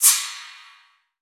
FX CYMB 1.wav